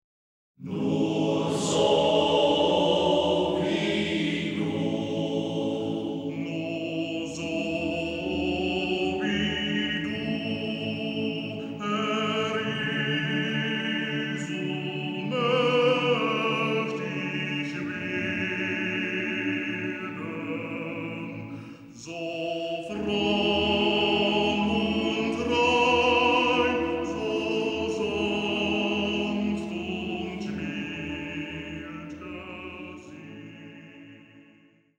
Männerchor